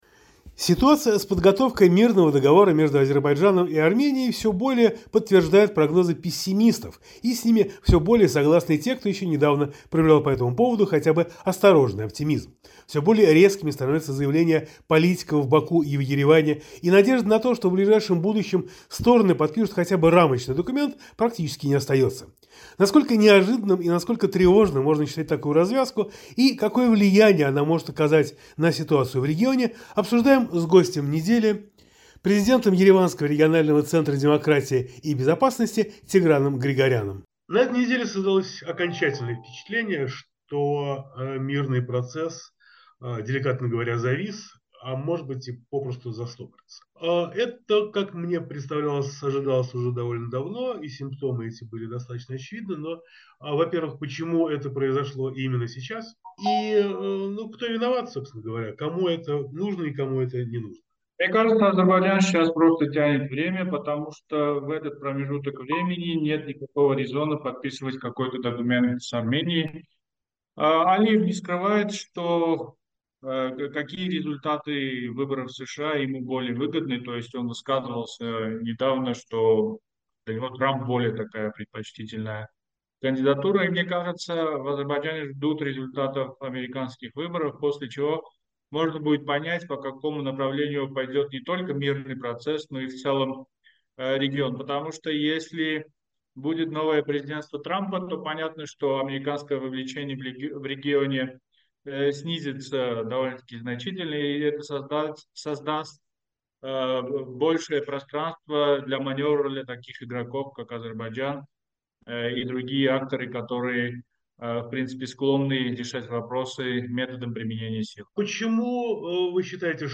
Гость недели политолог